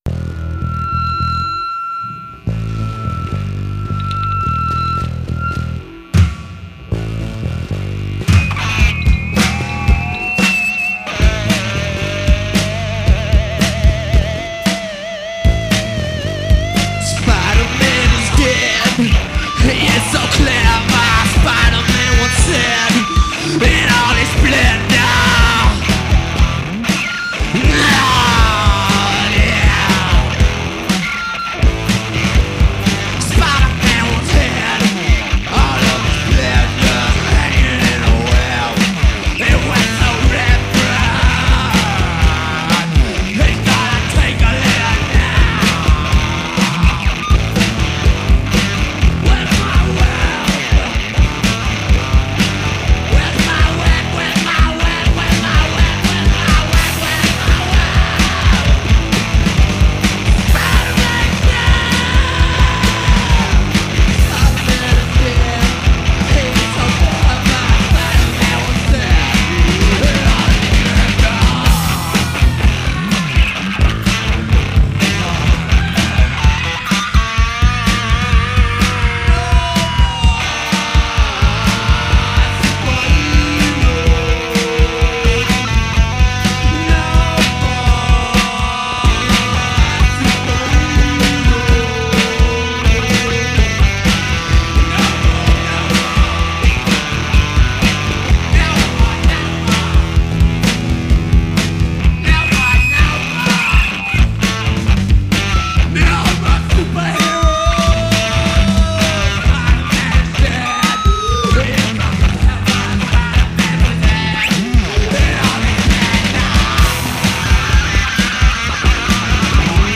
Recorded September 1987 to March 1990
Guitar, Vocals
Keyboards, Bass, Vocals